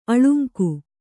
♪ aḷuŋku